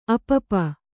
♪ apapa